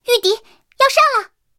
三号开火语音1.OGG